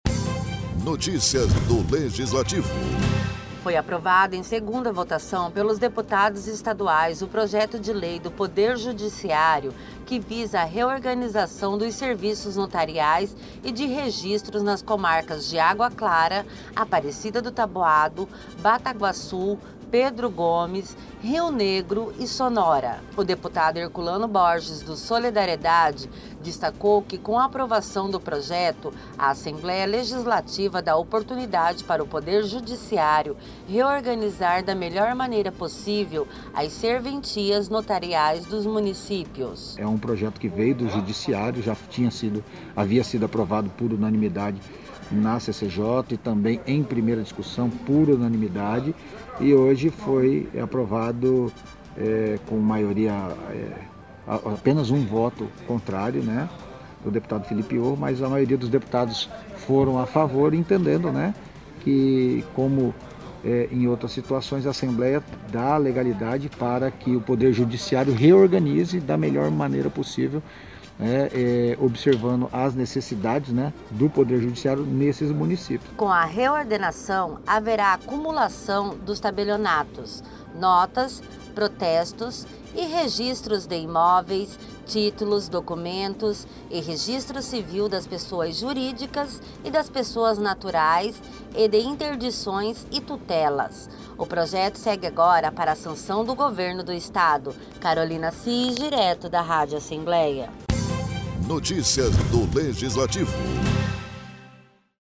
Locução e Produção: